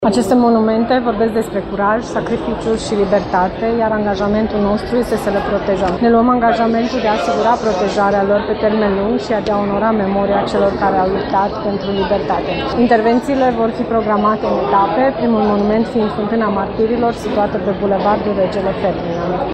Decizia a fost luată în urma unui dialog cu Asociația Memorialul Revoluției, care a realizat monumentele imediat după Revoluție și care, acum, a susținut nevoia implicării autorităților locale, spune viceprimarul Paula Romocean.
Paula-Romocean.mp3